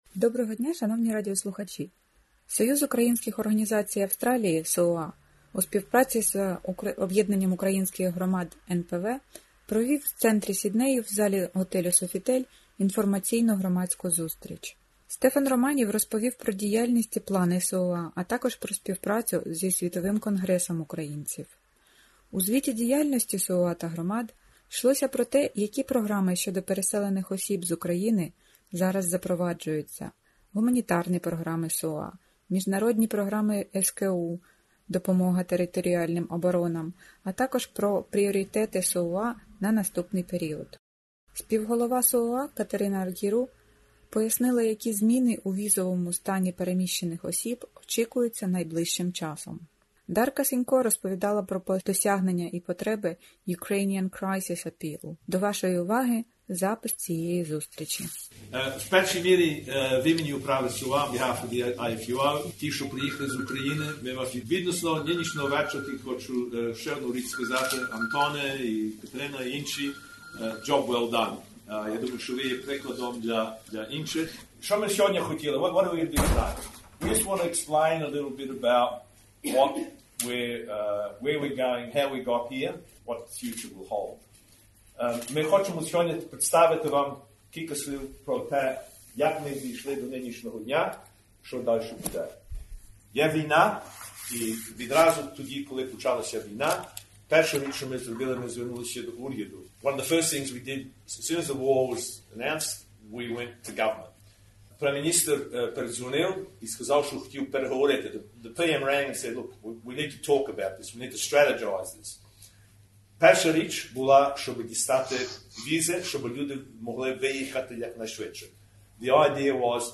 Cоюз Українських Організацій Aвстралії (СУОА) у співпраці з ОУГ НПВ (Об’єднання Українських Громад НПВ) провів в центрі Сіднею в залі готелю Софітель інформаційну громадську зустріч. Деякі витяги із повідомлень та виступів у часі цієї зустрічі й пропонуємо вашій увазі...